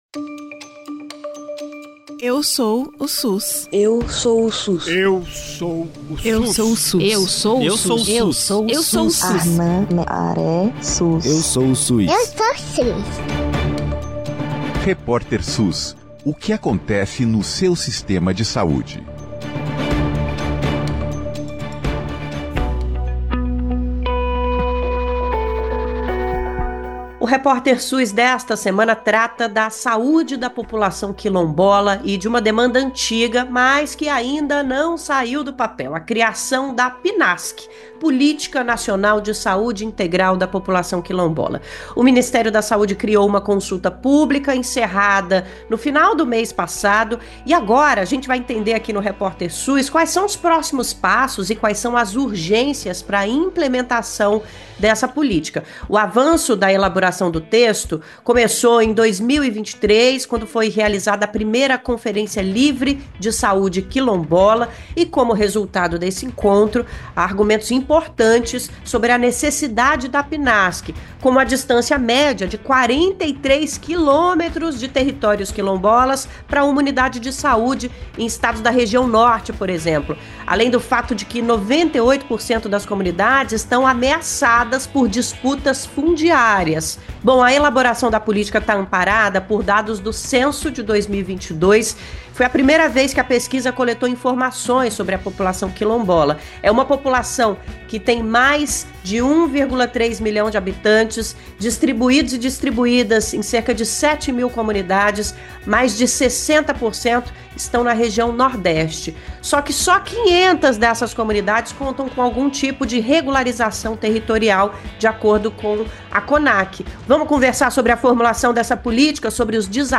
Em entrevista ao podcast Repórter SUS